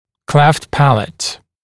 [kleft ‘pælət][клэфт ‘пэлэт]расщелина неба